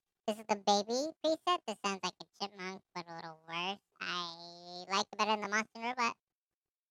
The SC3 comes with a voice changer feature.
Baby